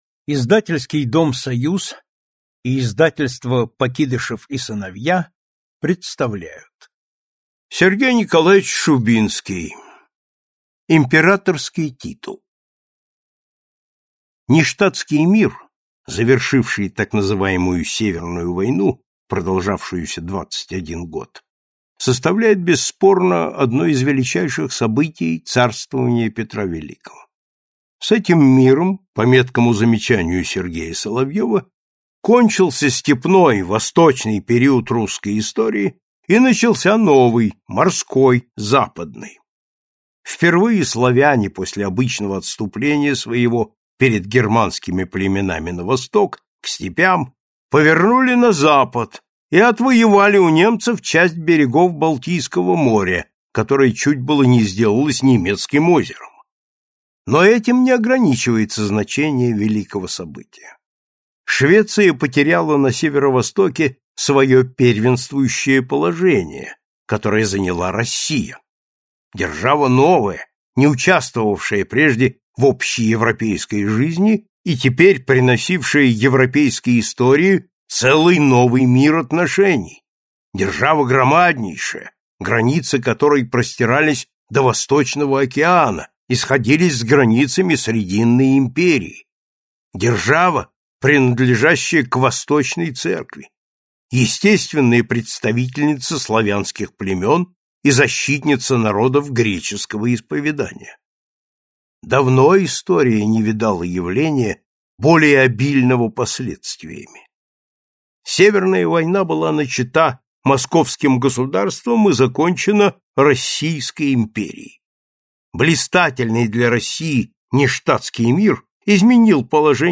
Aудиокнига Императорский титул